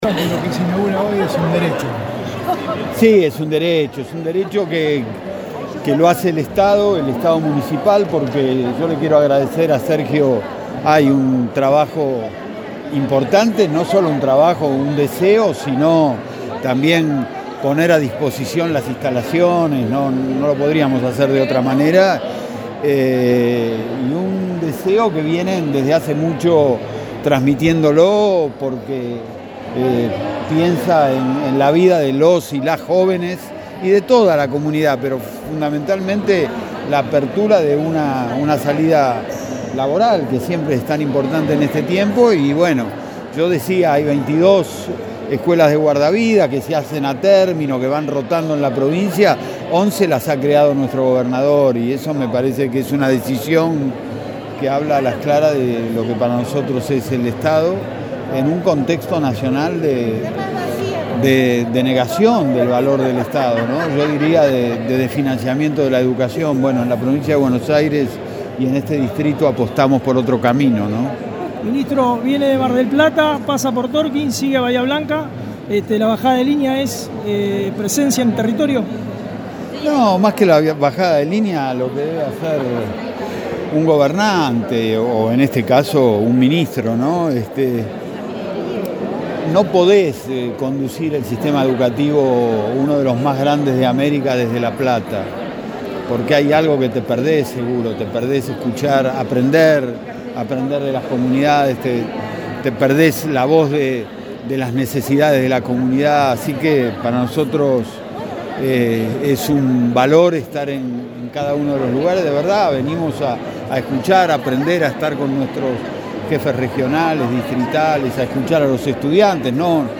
Este martes por la tarde, en el Gimnasio municipal "Eva Perón", se realizó el acto de apertura de la Escuela de Guardavidas en Tornquist.
Nota Sergio Bordoni y Alberto Sileoni